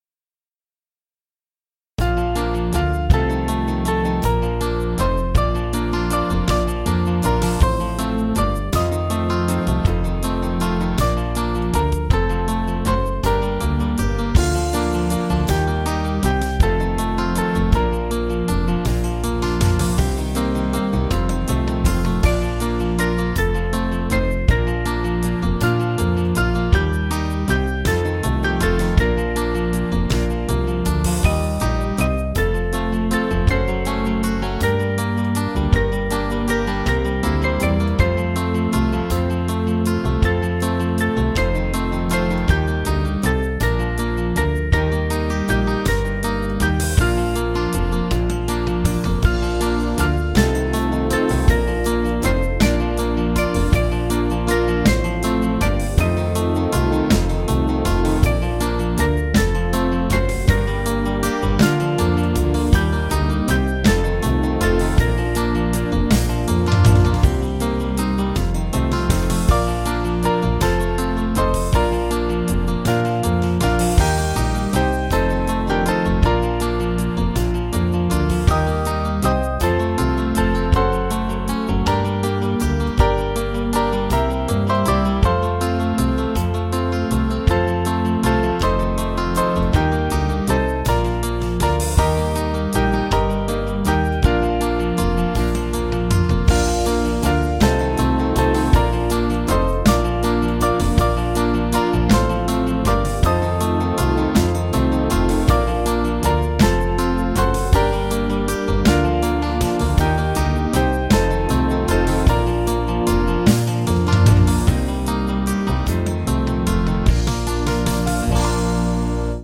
Small Band
(CM)   3/Eb 483.3kb